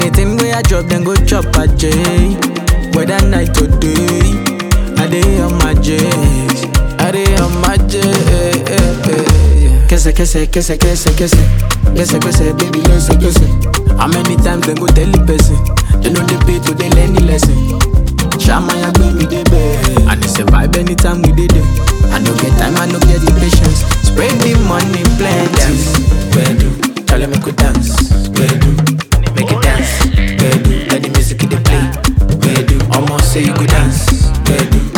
Жанр: Африканская музыка
# Afrobeats